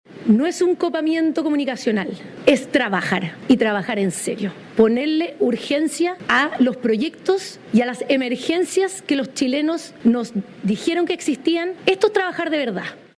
Ante esto, la portavoz del Palacio, la ministra Sedini, intentó despejar las dudas y dijo que trabajar y mostrar gestión no es “copar la agenda”.